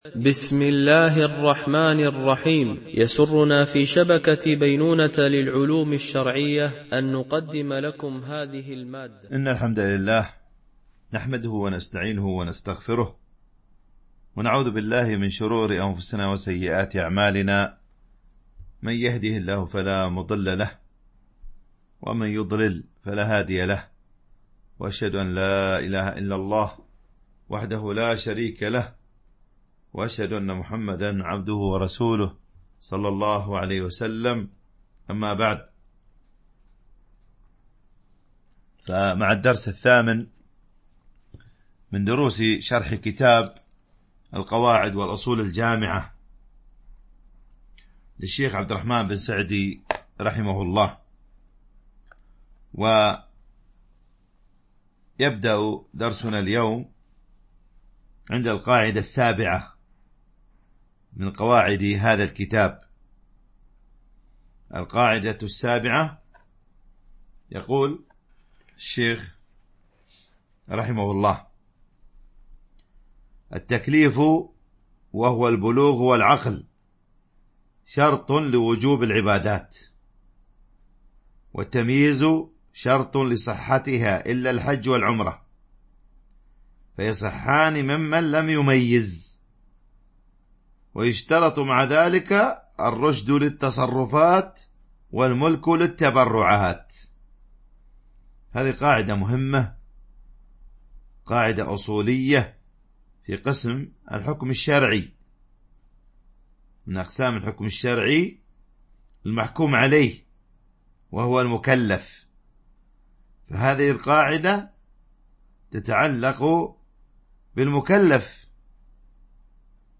شرح القواعد والأصول الجامعة والفروق والتقاسيم البديعة النافعة - الدرس 8 ( المكلف وشروط التكليف )